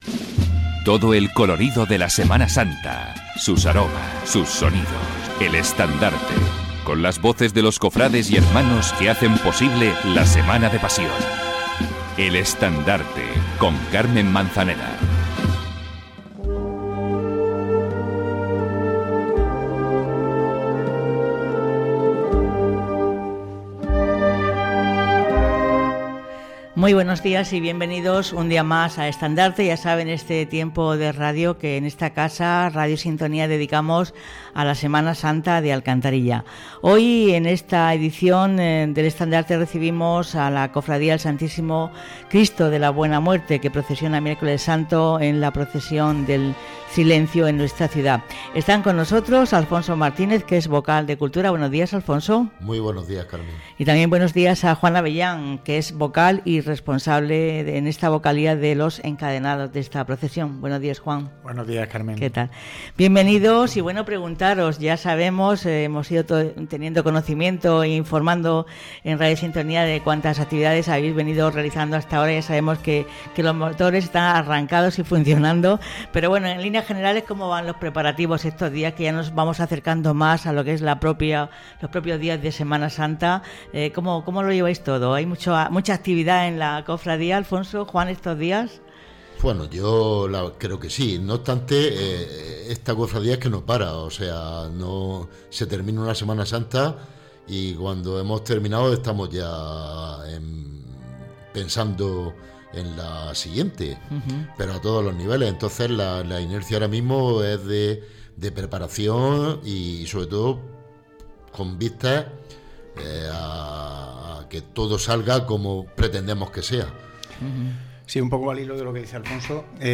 Escucha aquí el programa completo.
Nuestros invitados han hablado de los preparativos para la procesión del silencio del Miércoles Santo, destacando la actividad continua de la cofradía durante todo el año y su creciente integración con la parroquia. En la entrevista se ha comentado las diversas iniciativas para involucrar a la comunidad, como una exposición diorama, un concierto de bandas, un viacrucis por el barrio y la estación penitencial de las Siete Palabras, buscando realzar la Semana Santa local.